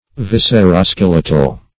Search Result for " visceroskeletal" : The Collaborative International Dictionary of English v.0.48: Visceroskeletal \Vis`cer*o*skel"e*tal\, a. (Anat.)